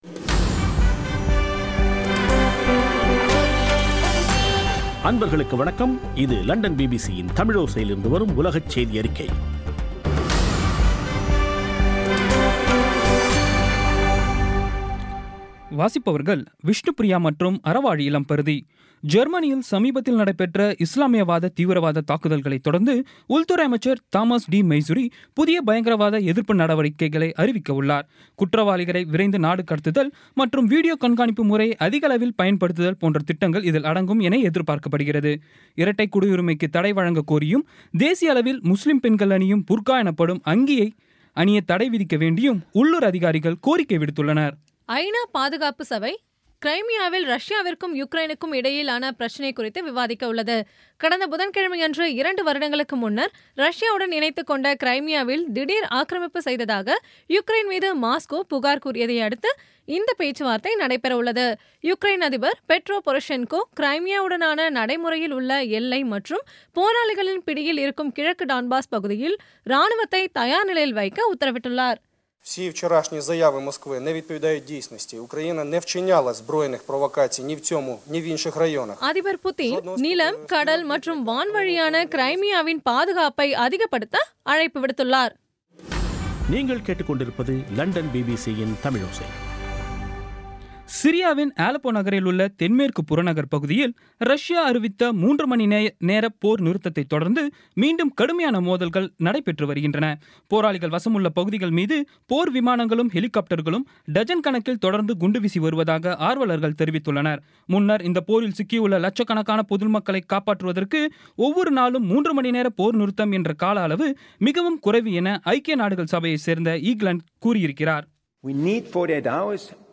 இன்றைய (ஆகஸ்ட் 11ம் தேதி ) பிபிசி தமிழோசை செய்தியறிக்கை